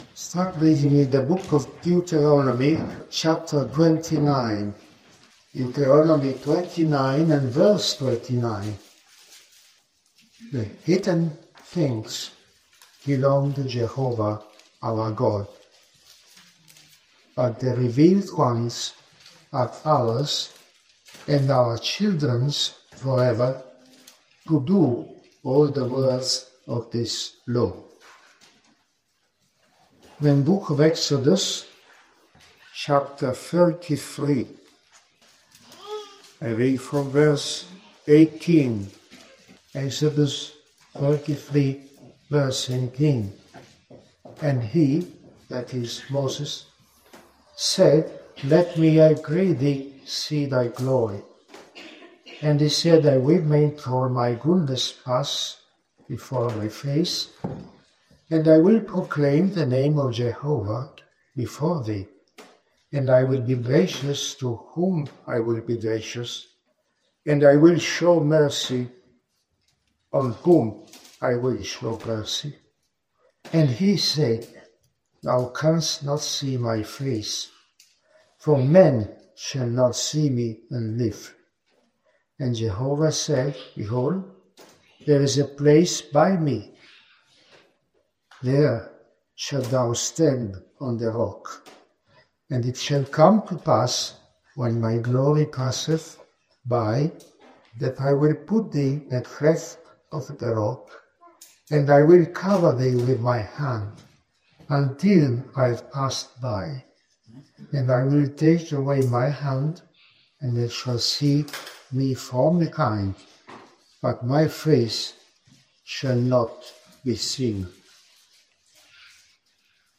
A Bible teaching on “The Hidden Things,” exploring how God conceals certain mysteries while revealing others for our faith and obedience. This message encourages believers to trust His wisdom, treasure His Word, and walk in the revelation given through His Spirit.